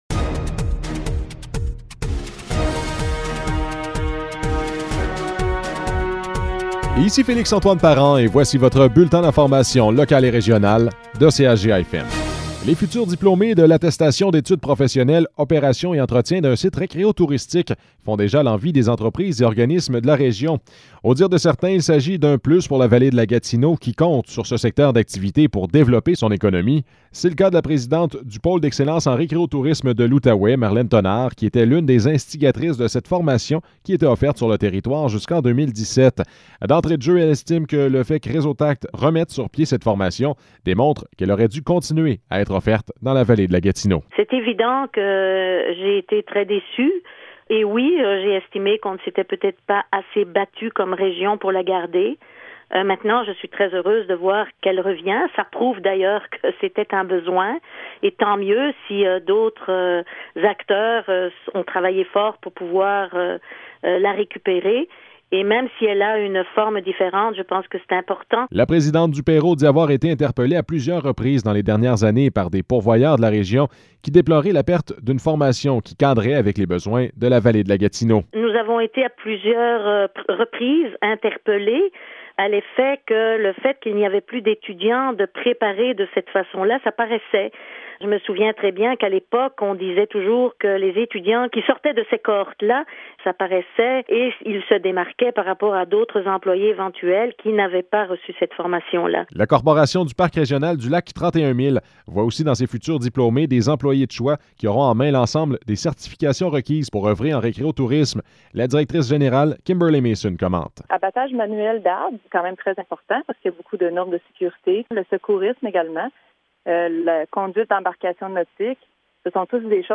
Nouvelles locales - 19 janvier 2021 - 12 h